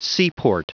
Prononciation du mot seaport en anglais (fichier audio)
Prononciation du mot : seaport